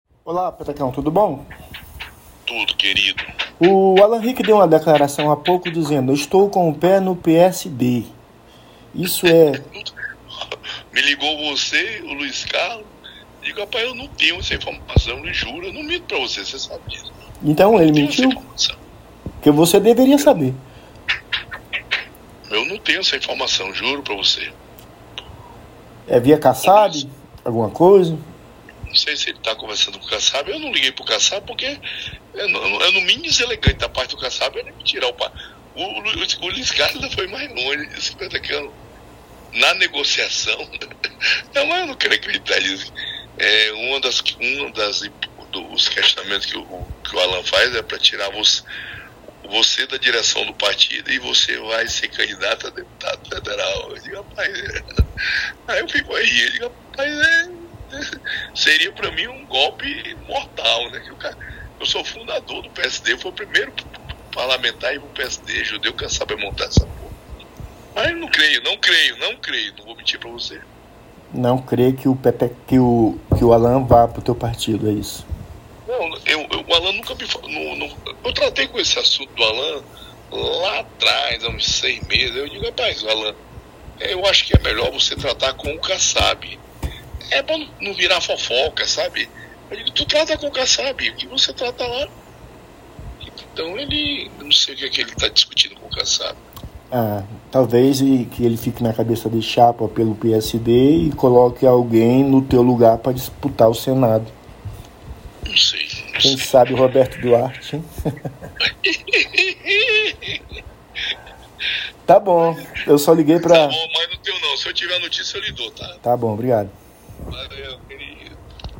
Ouça a entrevista com o senador logo abaixo: